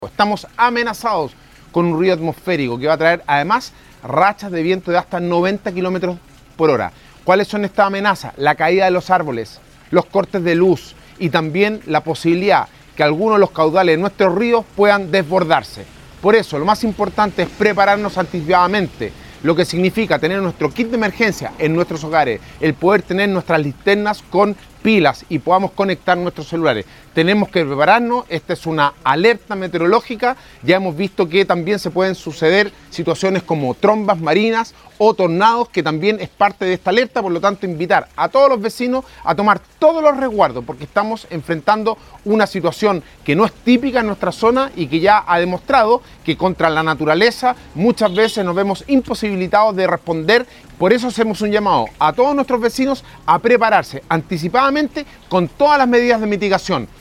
Alcalde-Sebastian-Alvarez-explica-las-emergencias-que-enfrenta-la-comuna-.mp3